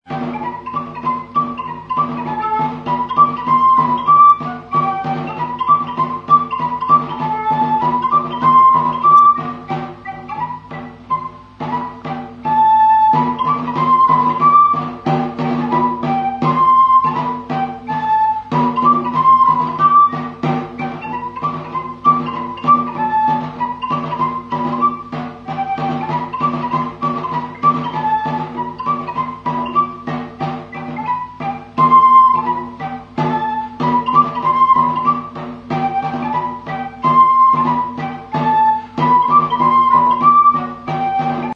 Aerófonos -> Flautas -> Recta (de una mano) + flautillas
SHEPHERS HEY. Headington (Oxford).
TABOR PIPE